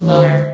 S.P.L.U.R.T-Station-13 / sound / vox_fem / lower.ogg
CitadelStationBot df15bbe0f0 [MIRROR] New & Fixed AI VOX Sound Files ( #6003 ) ...